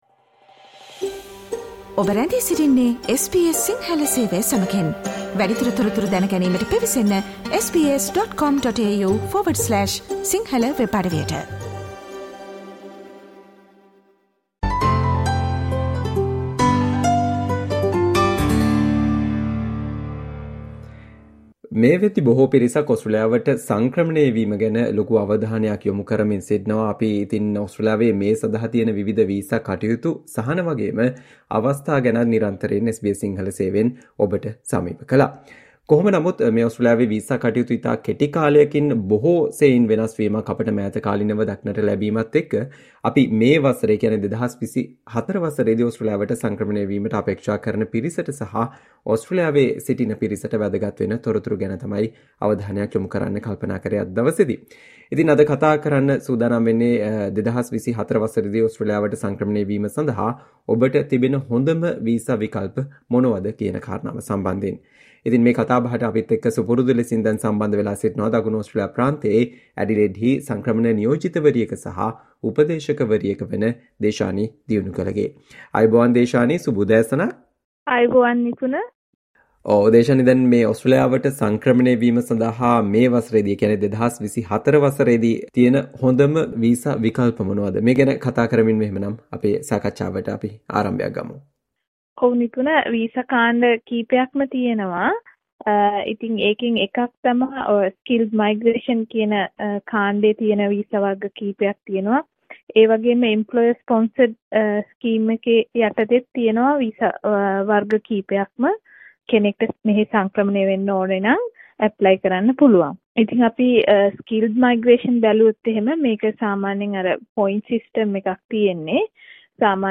SBS Sinhala discussion on Top visa options for migrating to Australia in 2024